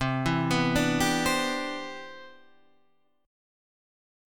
C Major 9th